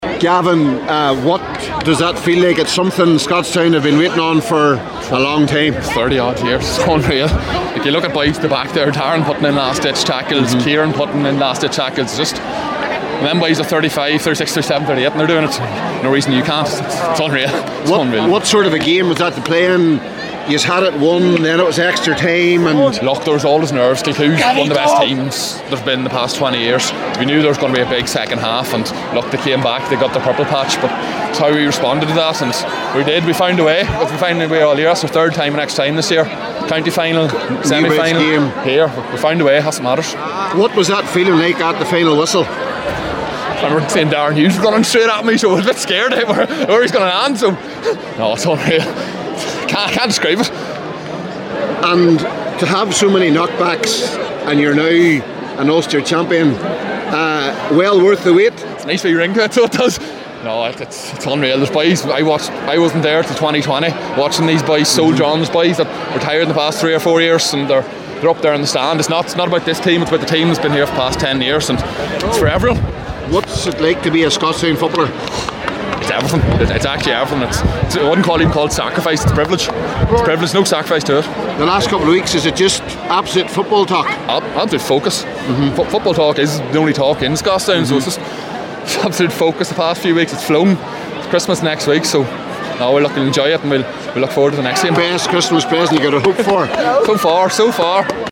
Post-match reaction